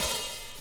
OpenHH Steveland.wav